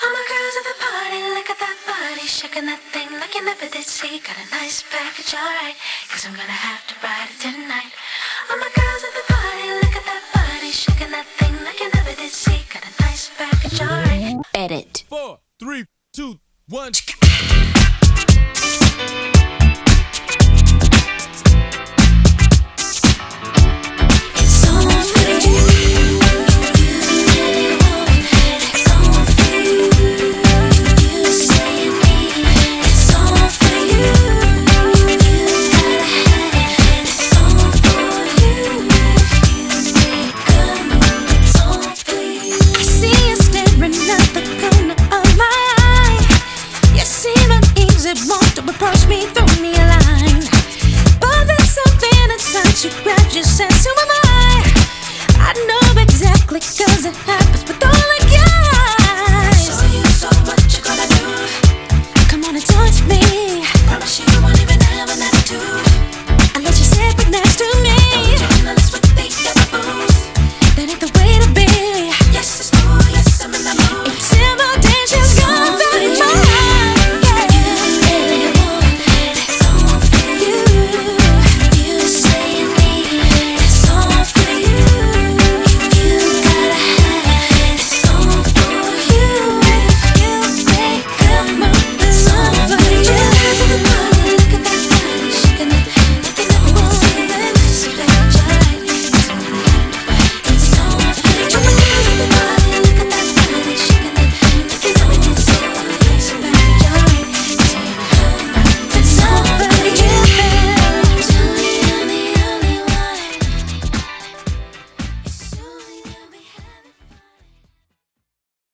BPM114
Audio QualityLine Out